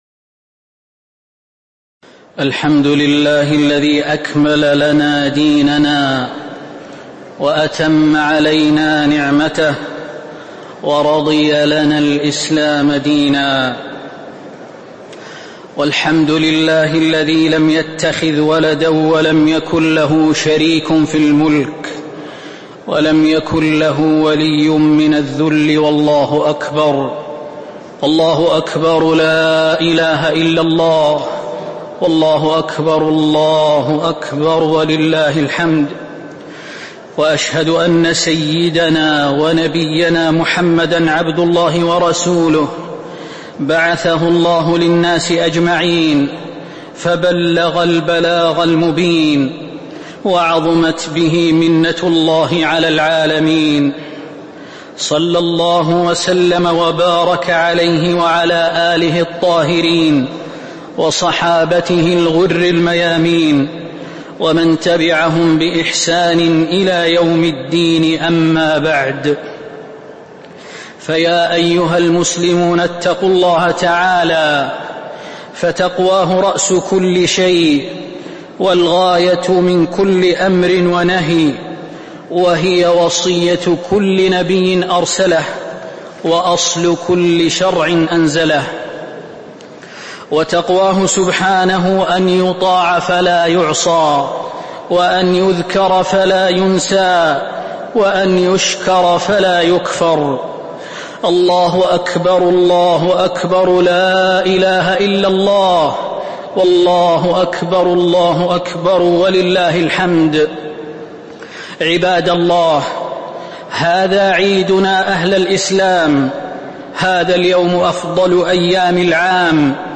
خطبة عيد الأضحى - المدينة - الشيخ خالد المهنا
تاريخ النشر ١٠ ذو الحجة ١٤٤٥ هـ المكان: المسجد النبوي الشيخ: فضيلة الشيخ د. خالد بن سليمان المهنا فضيلة الشيخ د. خالد بن سليمان المهنا خطبة عيد الأضحى - المدينة - الشيخ خالد المهنا